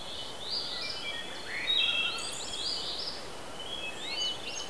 Birds8_3.wav